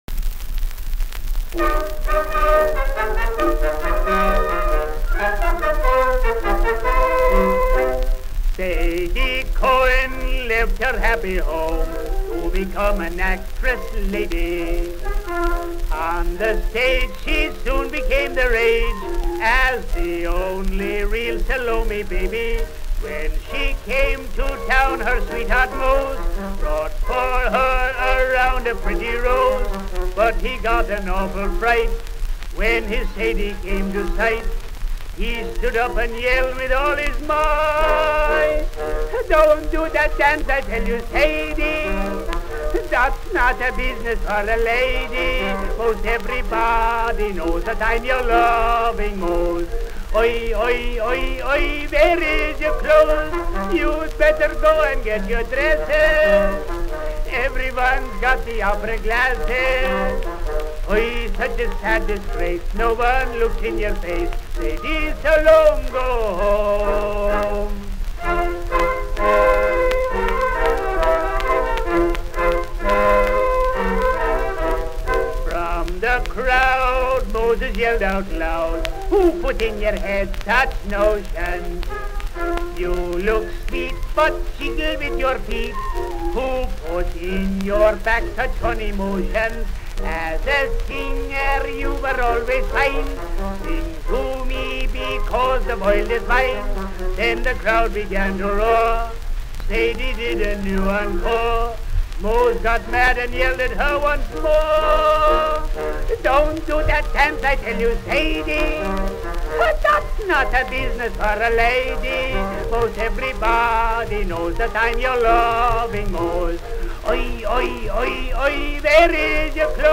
Novelty Song
Tin Pan Alley